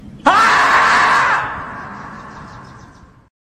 Nada notifikasi Marmot viral TikTok
Kategori: Nada dering
Keterangan: Nada notifikasi WA Marmot bikin banyak orang ngakak karena suaranya yang unik dan lucu banget.
nada-notifikasi-marmot-viral-tiktok-id-www_tiengdong_com.mp3